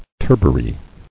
[tur·ba·ry]